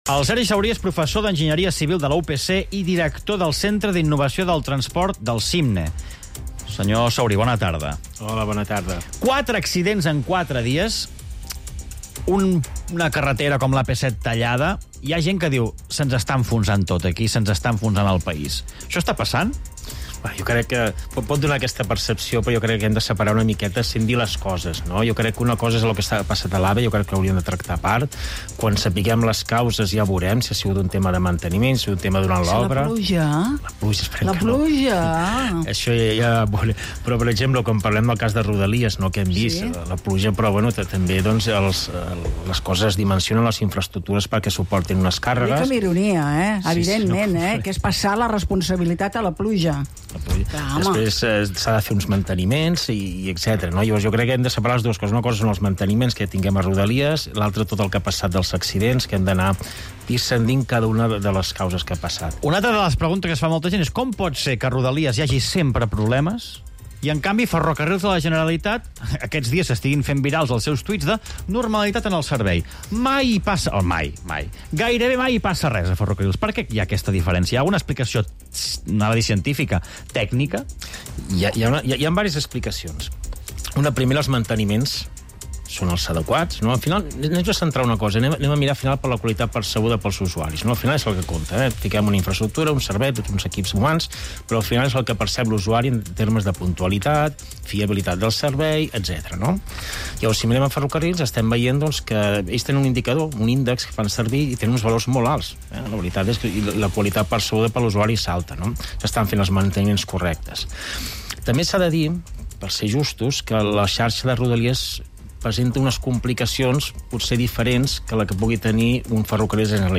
The researcher has been interviewed by local media 3CatInfo, Catalunya Ràdio, Cadena SER and COPE.